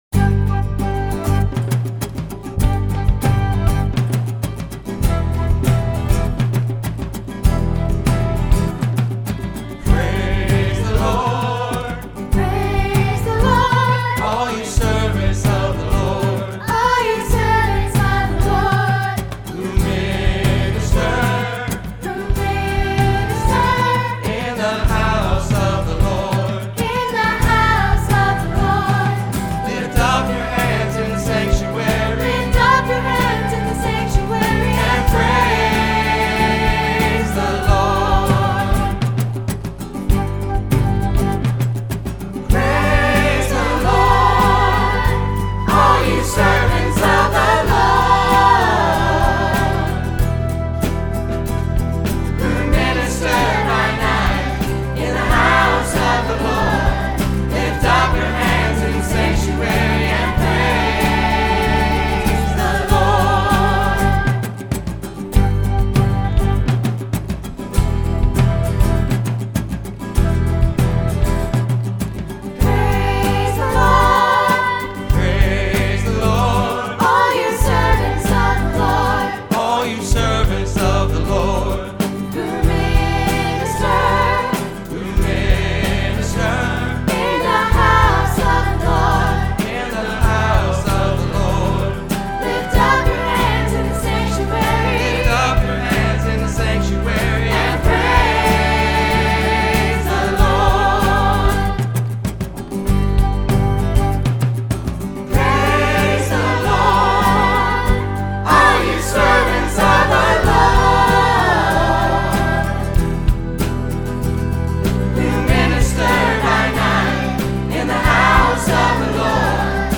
call-and-response song